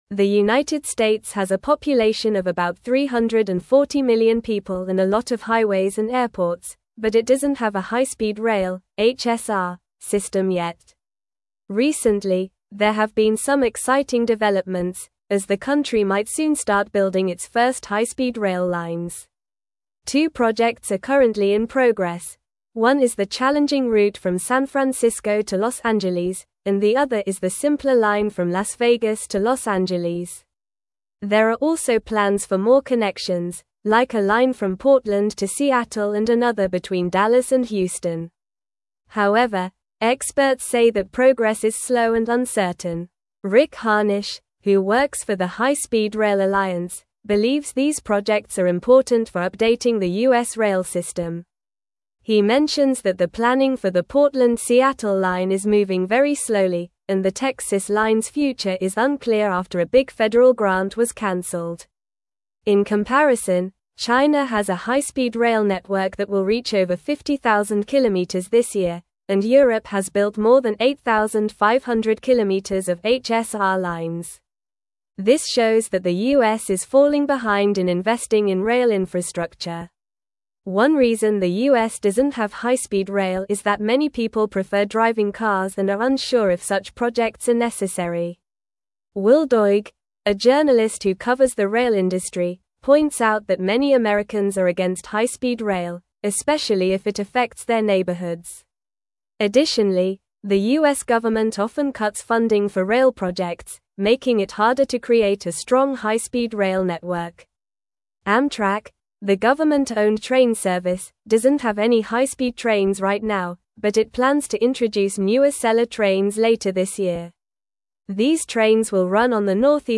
Normal
English-Newsroom-Upper-Intermediate-NORMAL-Reading-Challenges-and-Progress-of-High-Speed-Rail-in-America.mp3